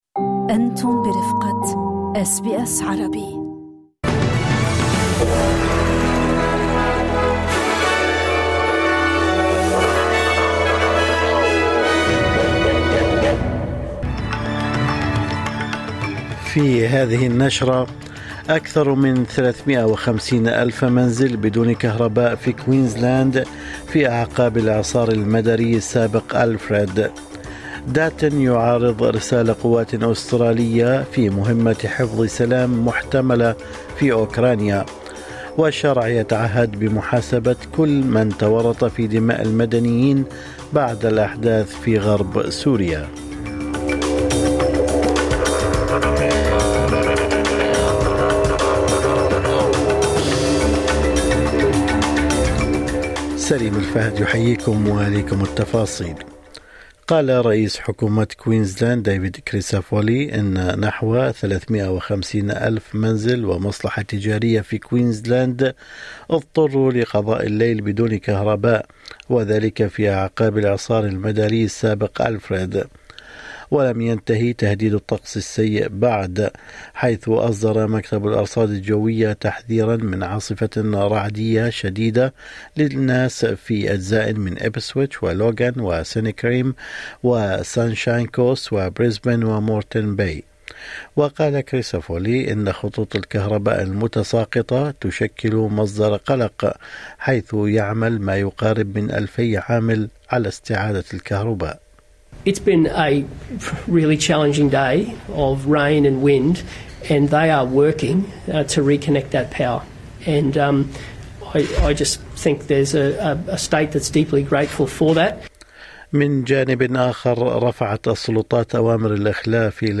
نشرة الأخبار